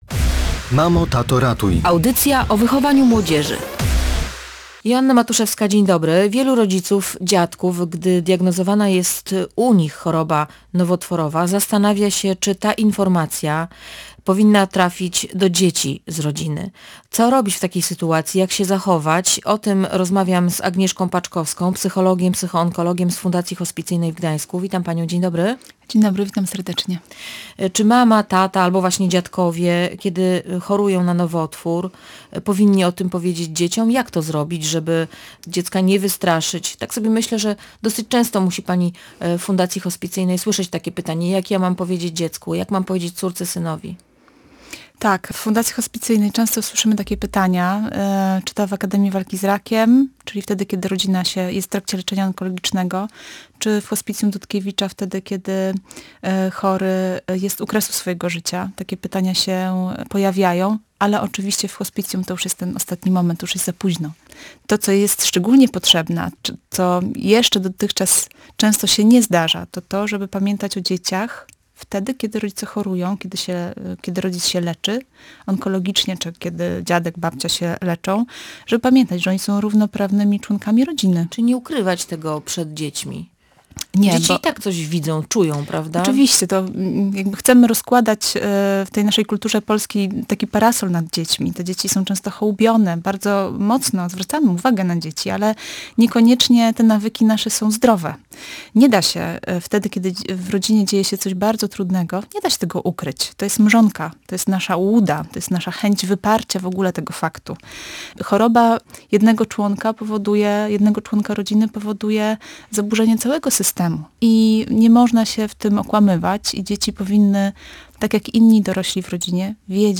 o tym w Radiu Gdańsk mówiła